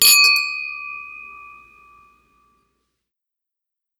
champagne-clink.mp3